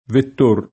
vett1re]: Vettor Pisani [vett1r piS#ni] o Vittor Pisani [vitt1r piS#ni], l’ammiraglio veneziano (1324-80); ma sempre senza tronc. Vittore Pisani [vitt1re piS#ni], il linguista (1899-1990) — sim. i cogn. Vettor [